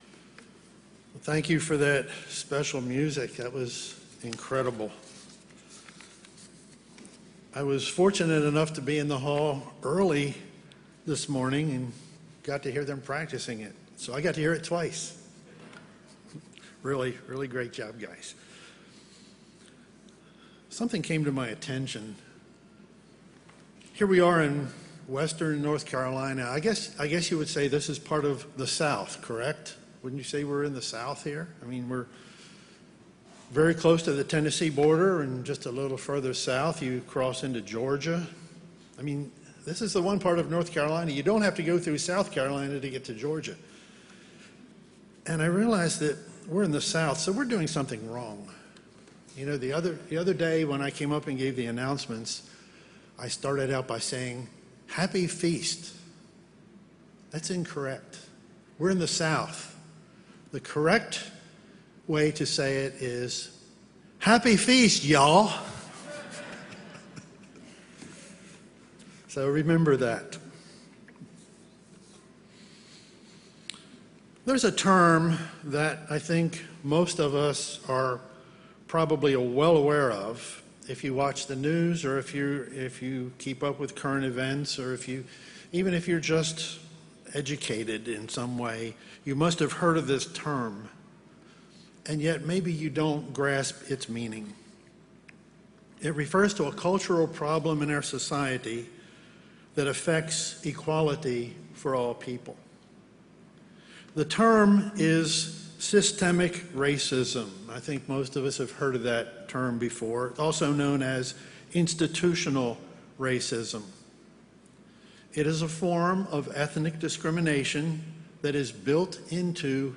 This sermon was given at the Lake Junaluska, North Carolina 2021 Feast site.